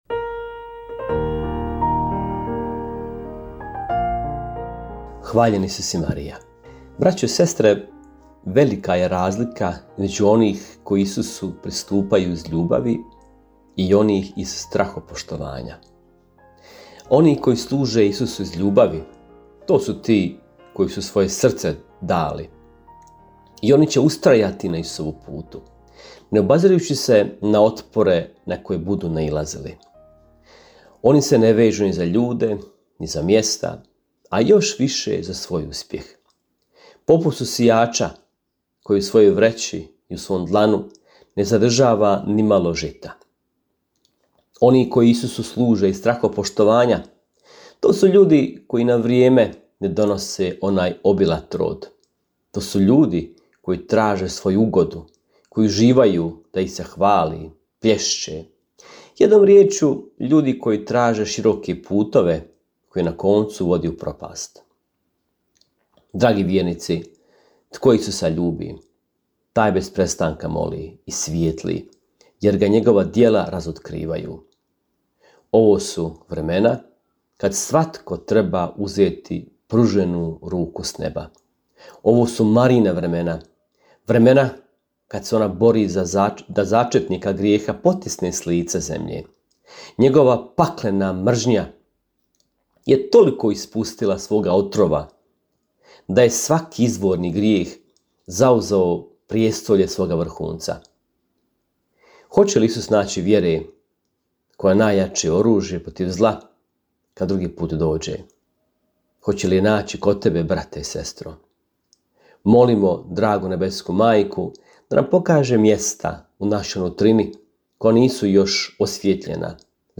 Kratku emisiju ‘Duhovni poticaj – Živo vrelo’ slušatelji Radiopostaje Mir Međugorje mogu čuti od ponedjeljka do subote u 3 sata, te u 7:10. Emisije priređuju svećenici i časne sestre u tjednim ciklusima.